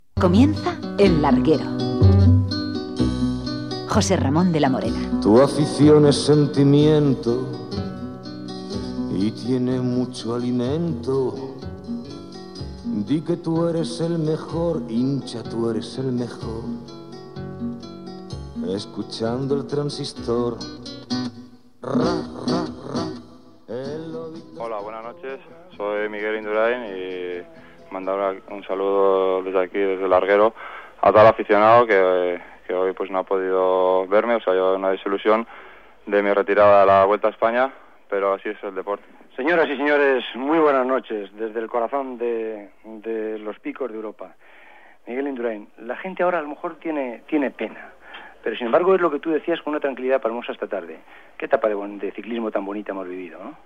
Careta del programa i Miguel Induráin saluda als aficionats des del final d'etapa de la Vuelta Ciclista a España als Picos d'Europa després de retirar-se
Esportiu